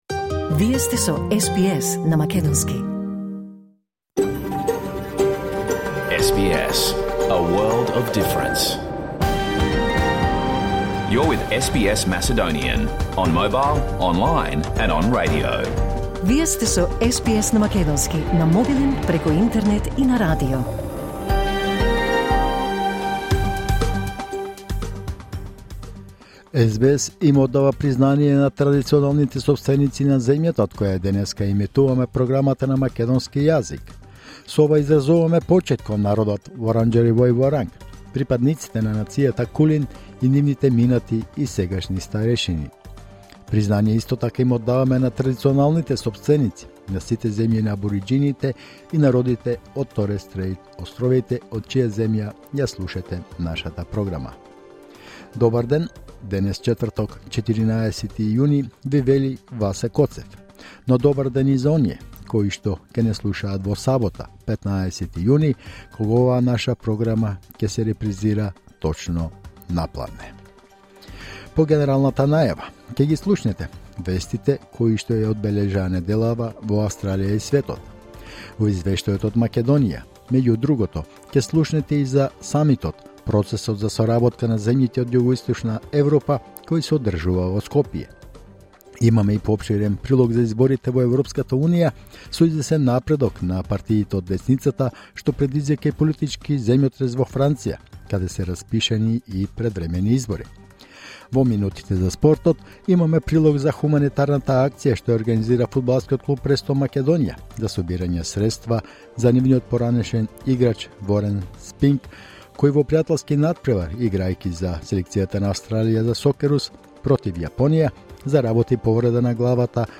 SBS Macedonian Program Live on Air 14 June 2024